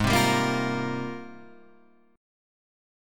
G#M13 chord